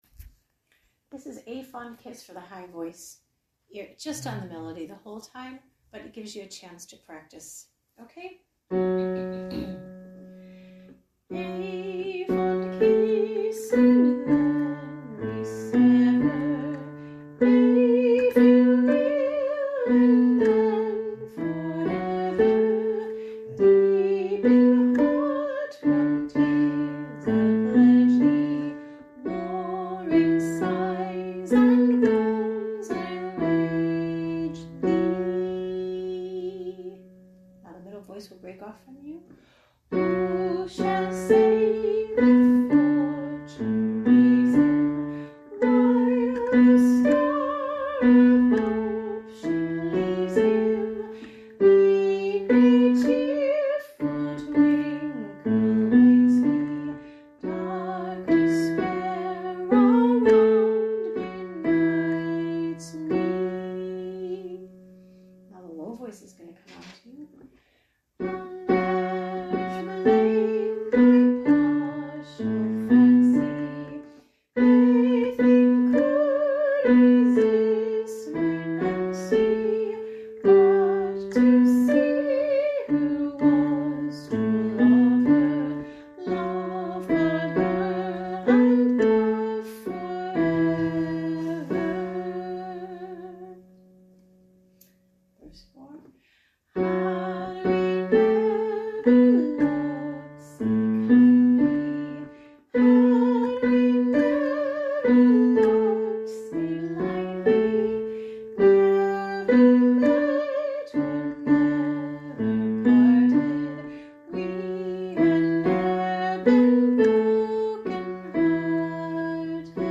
Sing Along Tracks (mp3):High VoiceMiddle VoiceLow Voice
High Voice